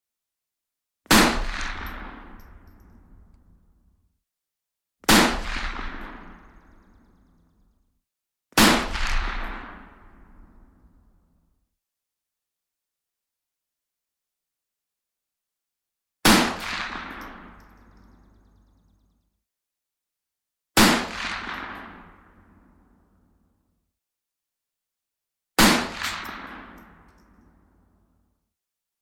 strel-ba-iz-vintovki_24609.mp3